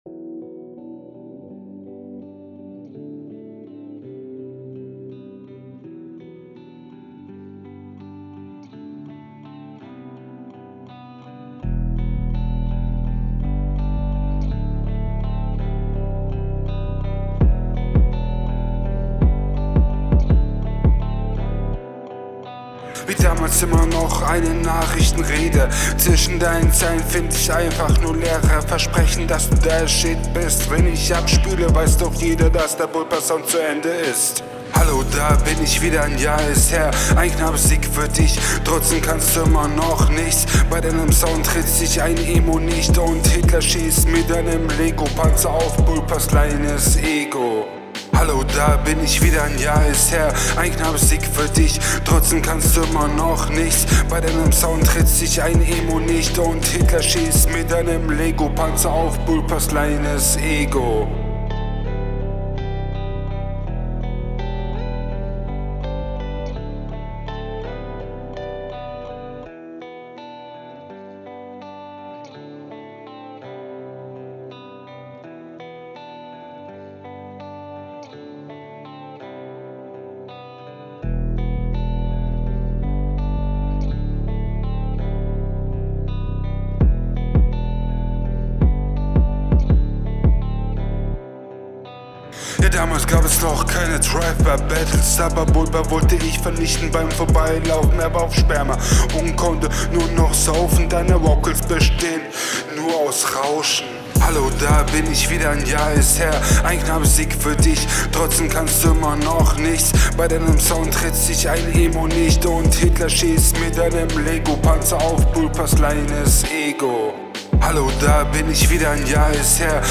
Es mangelt (noch) an Rhythmusgefühl (vielleicht wären dazu ein paar Übungen nicht schlecht).